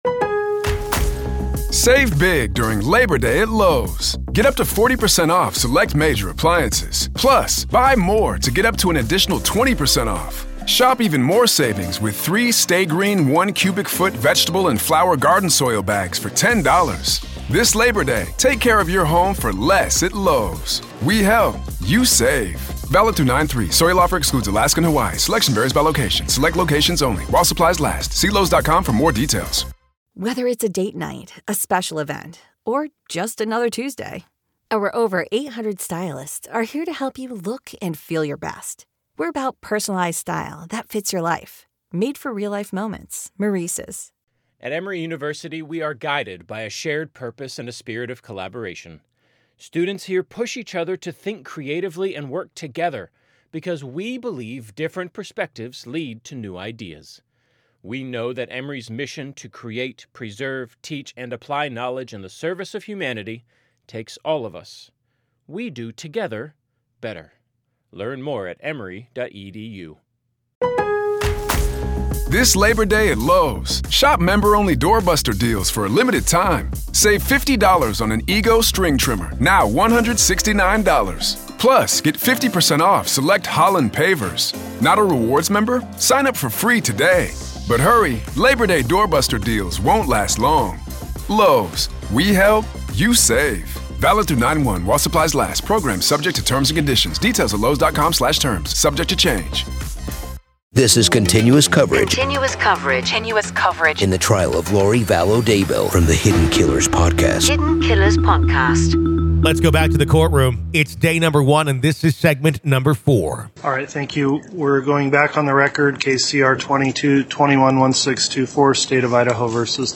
LISTEN: The Trial Of Lori Vallow Daybell Day 1 Part 4 | Raw Courtroom Audio